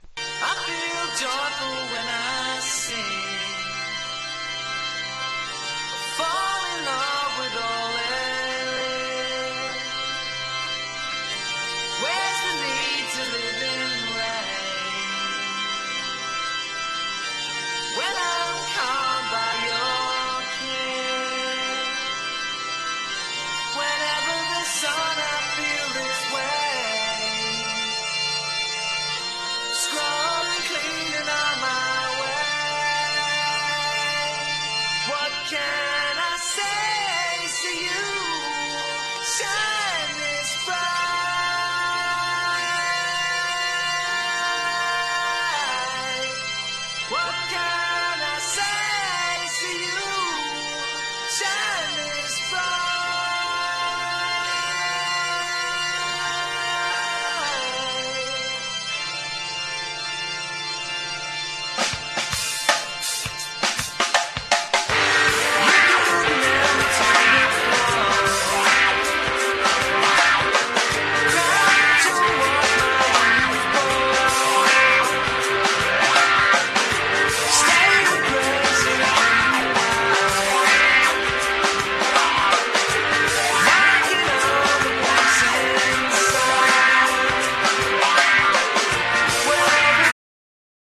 アコースティックなサウンドを中心に大らかなメロディー満載の1枚！